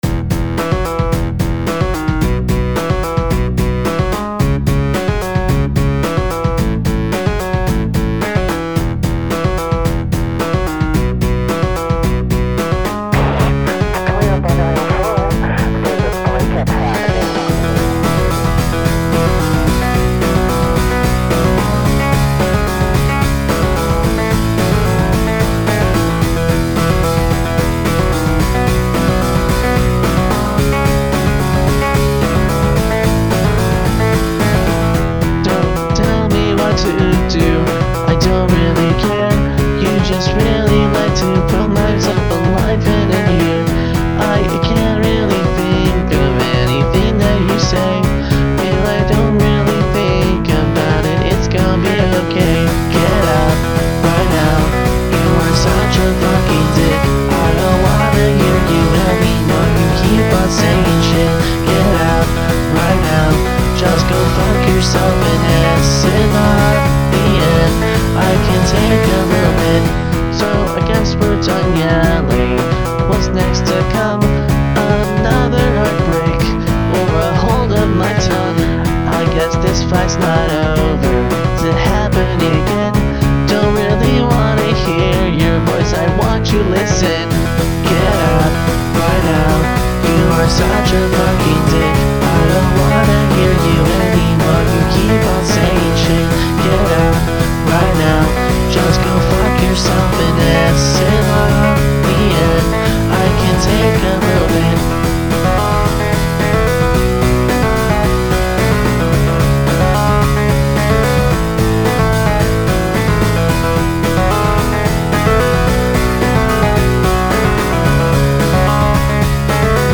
[ "pop-punk", "pop", "rock", "hyperpop" ]
[ "POP", "INDIE_ROCK", "ROCK" ]